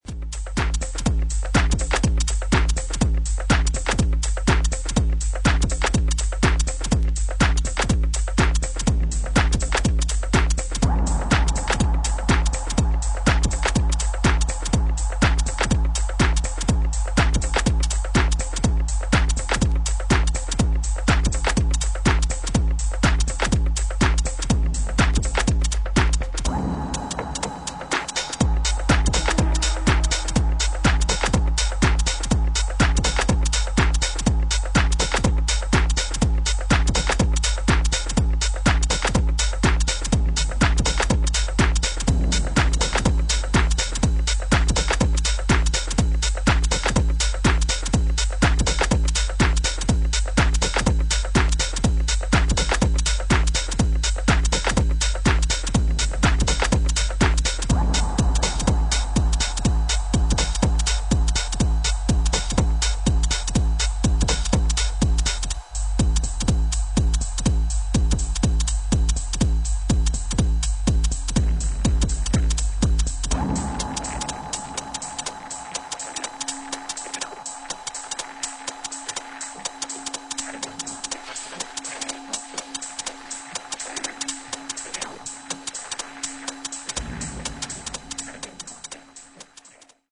』で構成された、クールなテックハウス4曲を収録。